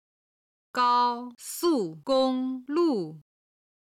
今日の振り返り！中国語発声
收费站 (shōu fèi zhàn)